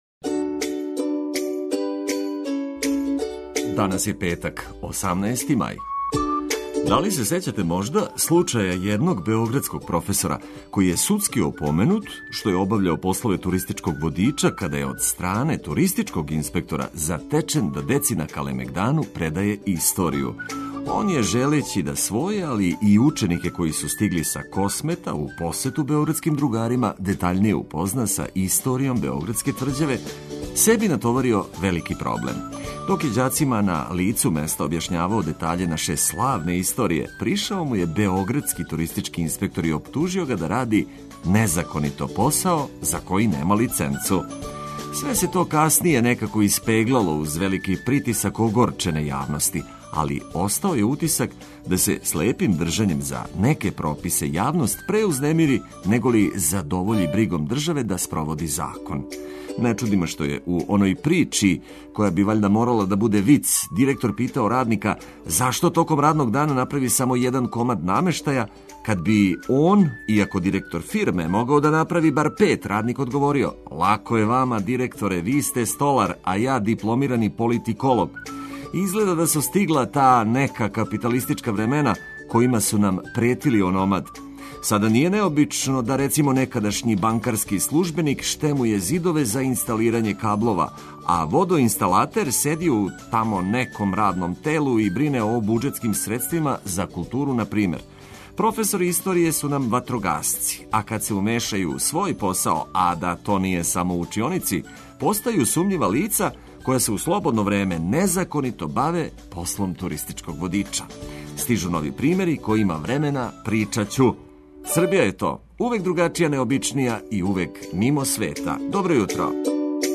Буђење уз корисне вести и ведру музику за устајање.